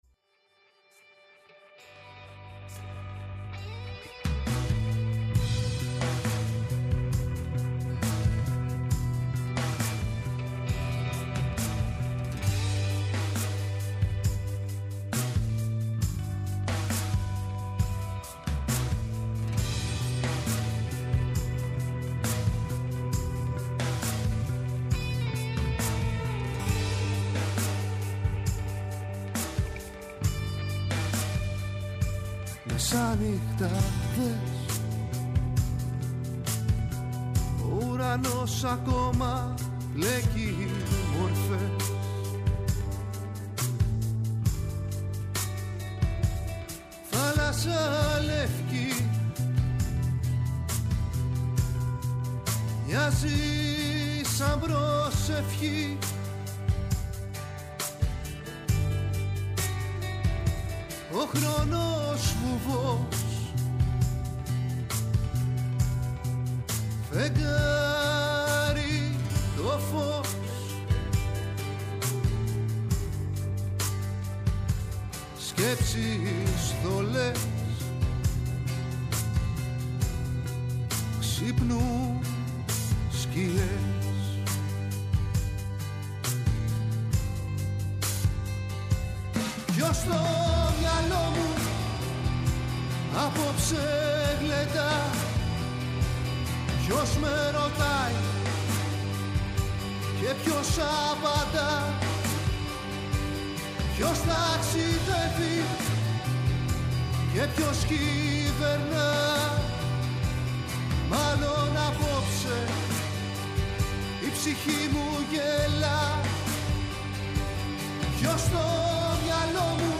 ΔΕΥΤΕΡΟ ΠΡΟΓΡΑΜΜΑ Live στο Studio Μουσική Συνεντεύξεις